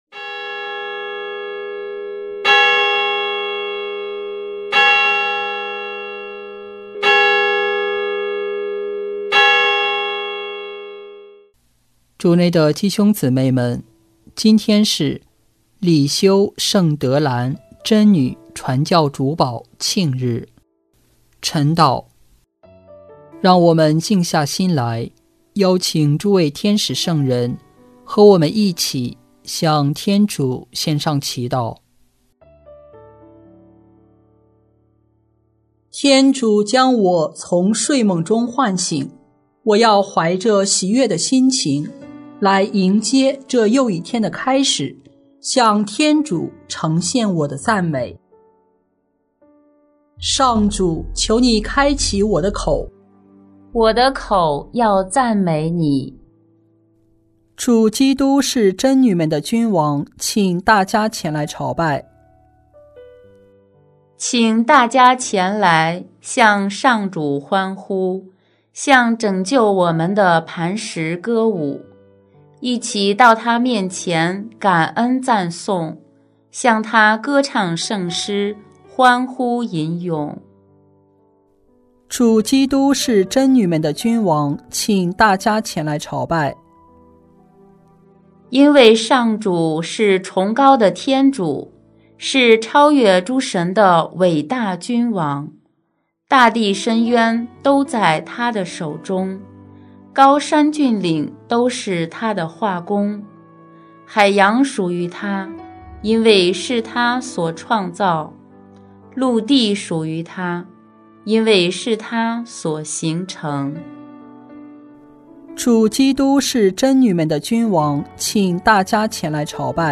【每日礼赞】|10月1日里修圣德兰贞女传教主保庆日晨祷